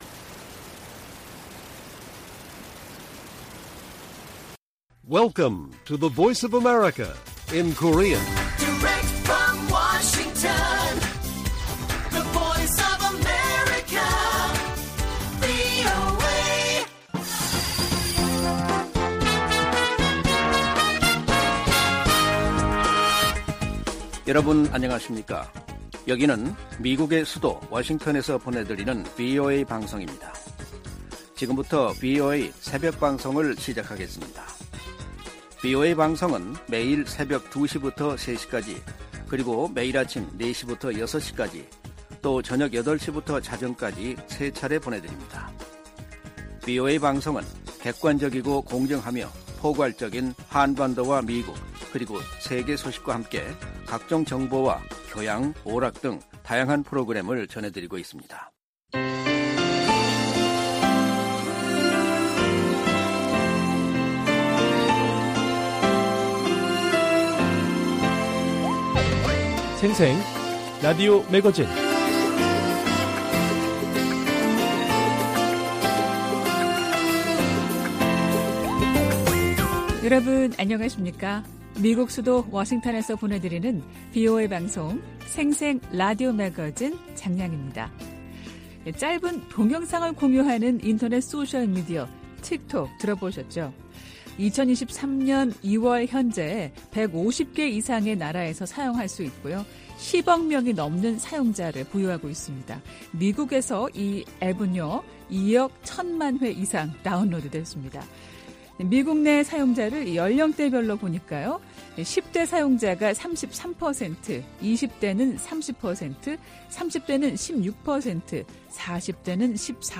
VOA 한국어 방송의 월요일 새벽 방송입니다.